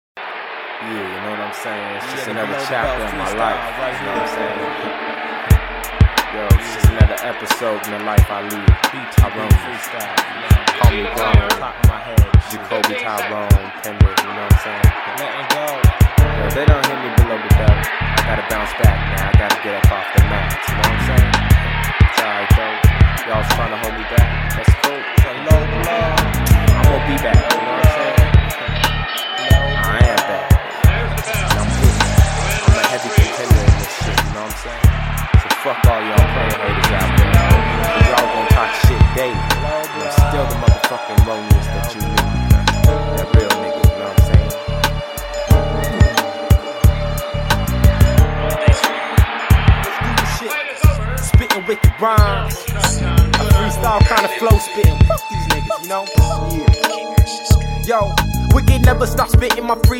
Genre: Rap & Hip-Hop.